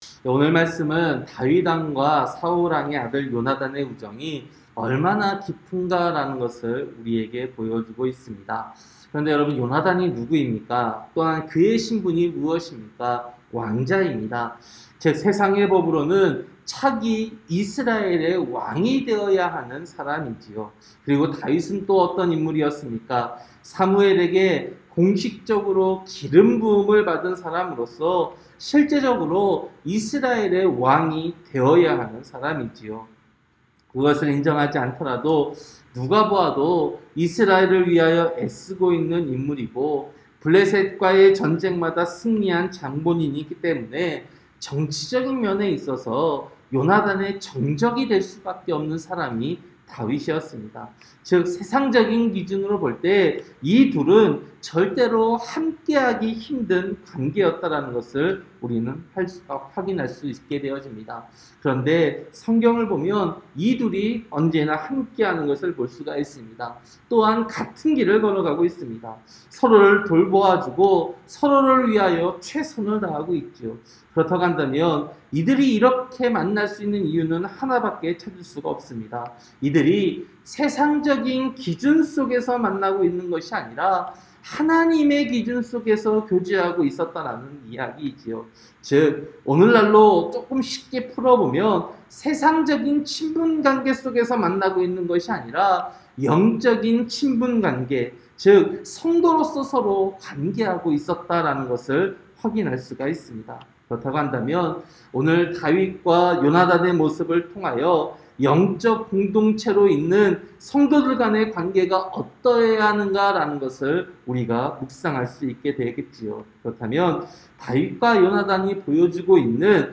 새벽설교-사무엘상 20장